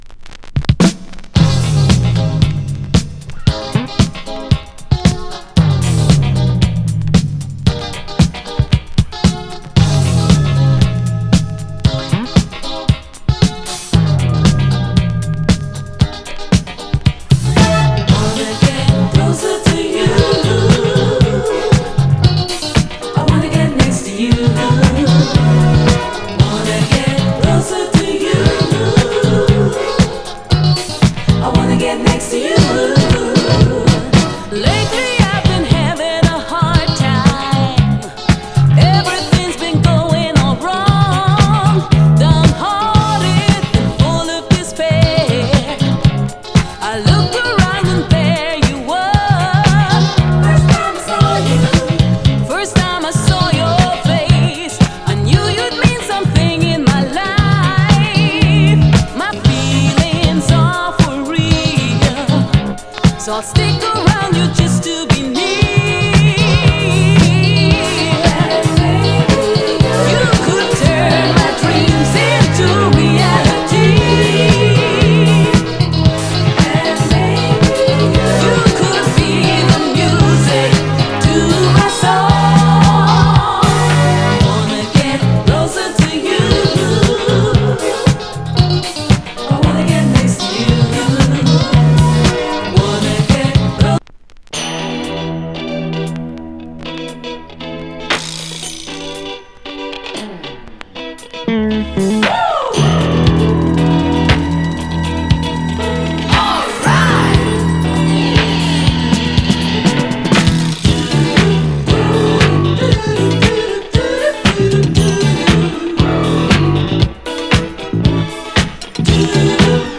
Genre:            Reggae, Funk / Soul
Style:               Boogie, Disco, Funk, Reggae